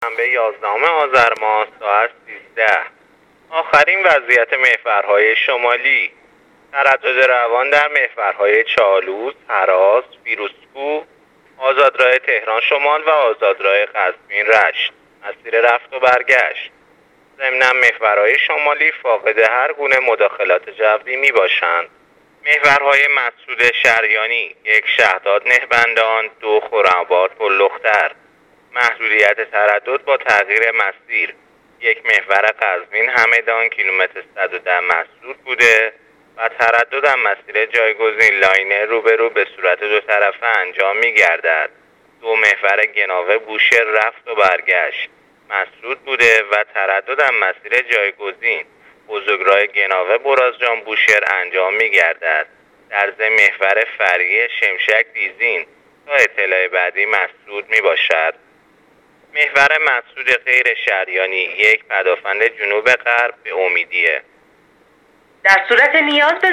گزارش رادیو اینترنتی از وضعیت ترافیکی جاده‌ها تا ساعت ۱۳ یازدهم آذرماه